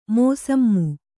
♪ mōsammu